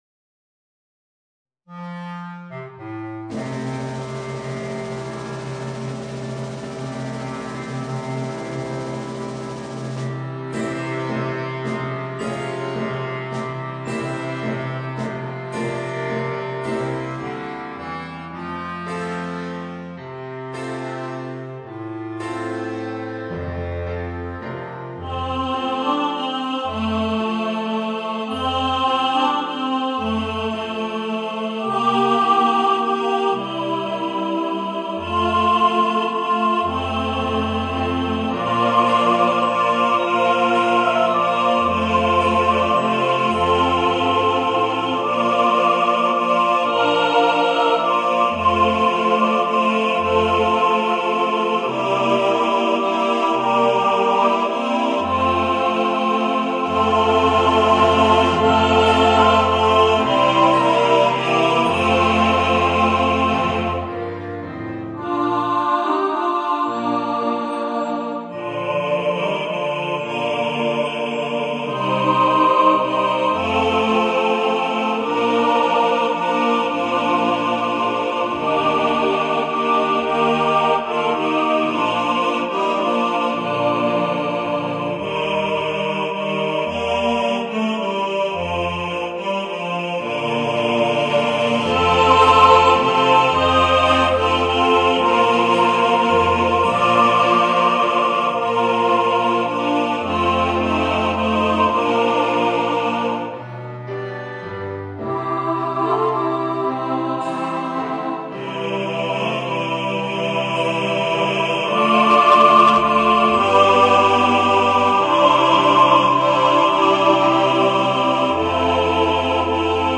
Voicing: Chorus